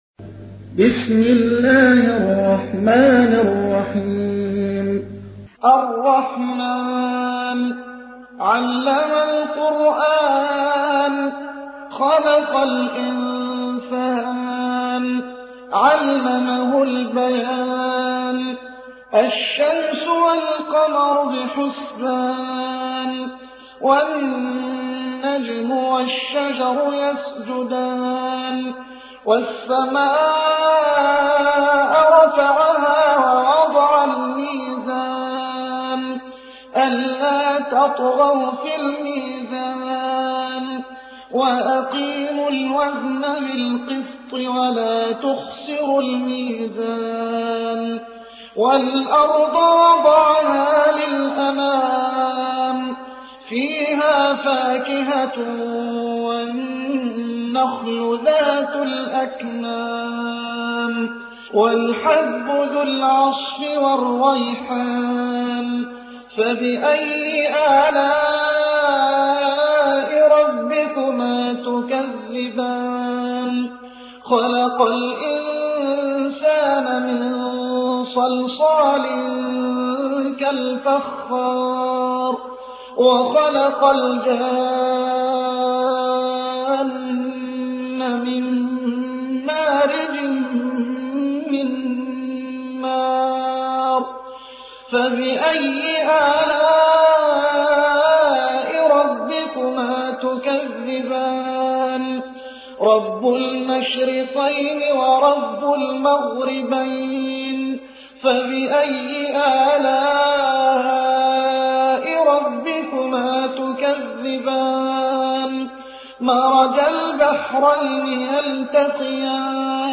تحميل : 55. سورة الرحمن / القارئ محمد حسان / القرآن الكريم / موقع يا حسين